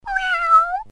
แมวร้อง